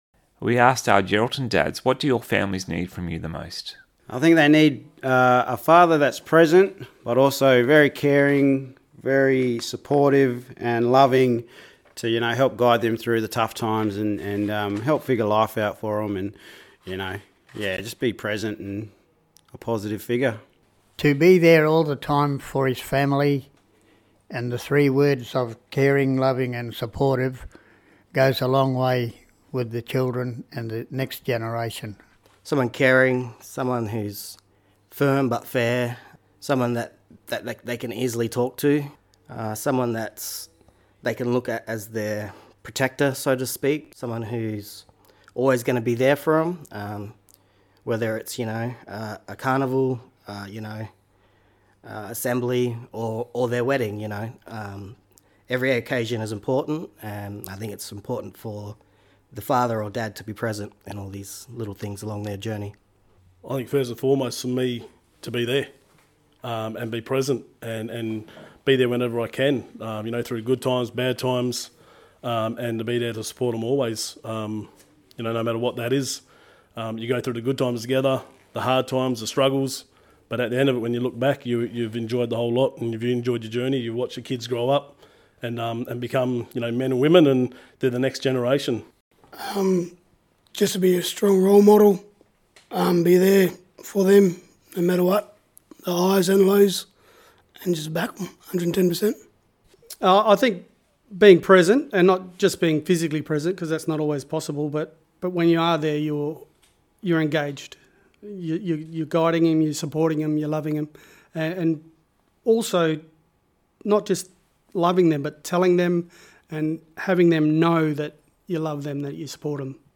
Together, they developed a campaign highlighting the importance of positive fatherhood, healthy masculinities, and the power of working together to prevent family violence. Seven local dads shared their voices and experiences, creating a series of radio announcements for Radio MAMA and social media posts that were shared widely across the community.
Their messages were heartfelt and authentic, drawing on real-life reflections about fatherhood and the role of men in shaping the next generation.